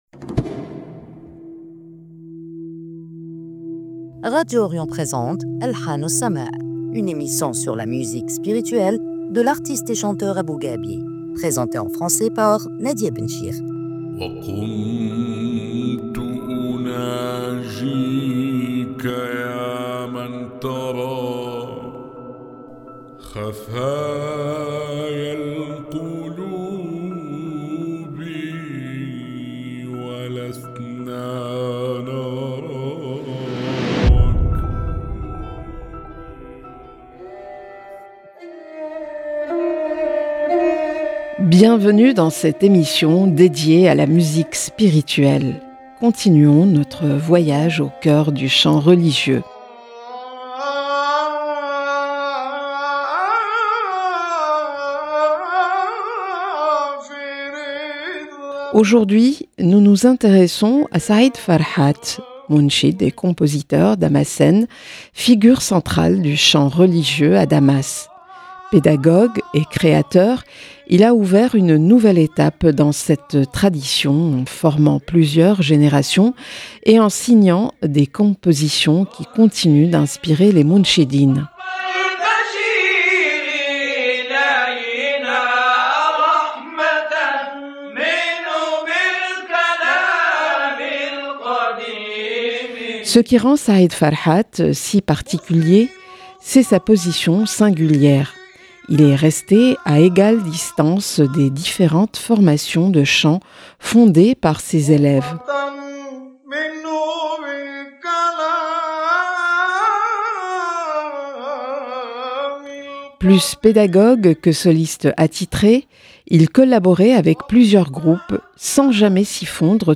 une émission sur la musique spirituelle